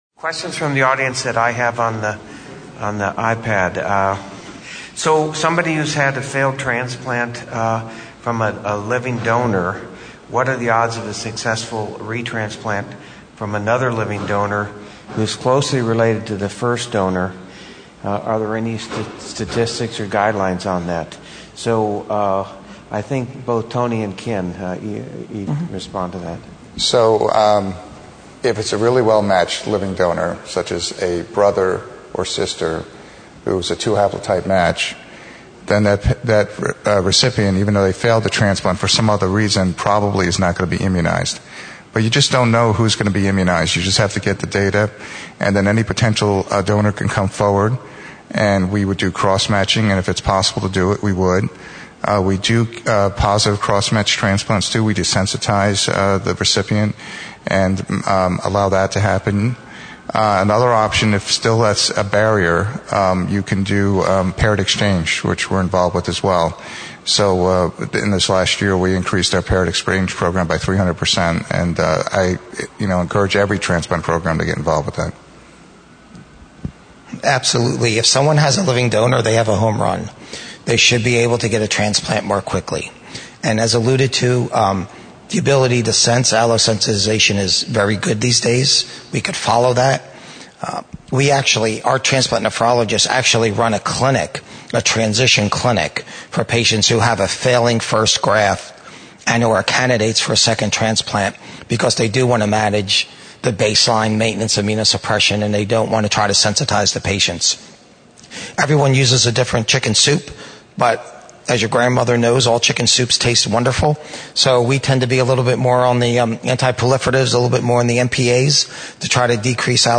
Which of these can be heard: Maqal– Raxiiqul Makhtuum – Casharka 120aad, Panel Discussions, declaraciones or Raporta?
Panel Discussions